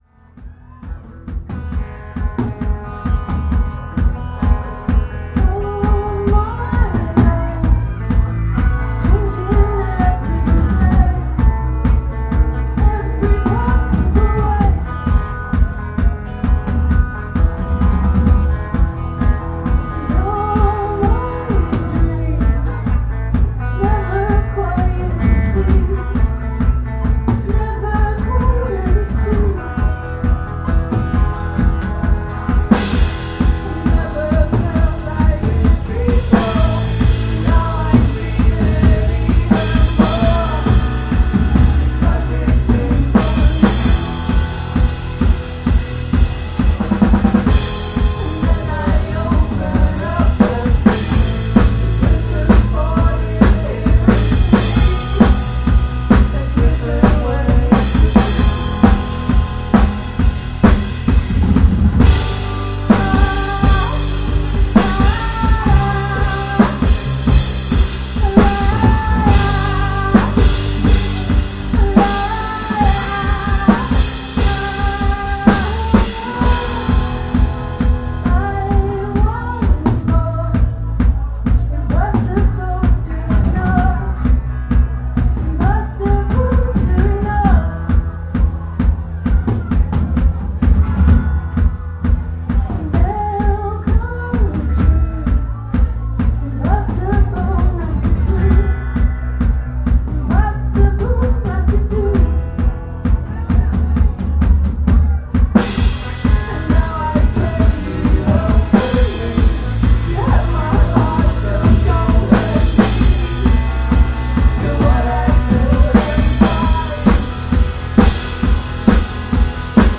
West Street Live, June 2024